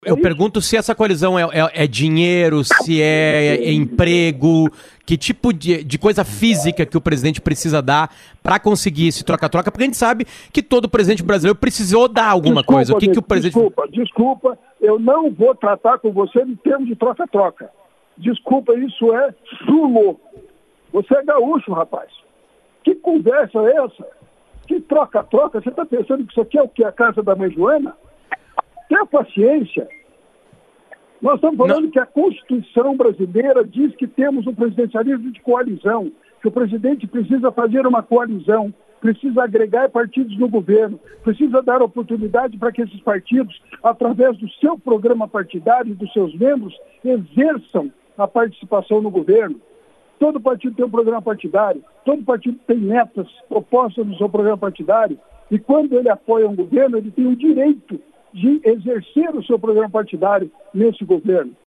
Ele se revoltou ao ouvir uma pergunta sobre “troca-troca” no governo e coisas “físicas” que o presidente Jair Bolsonaro precisaria dar aos aliados para garantir governabilidade.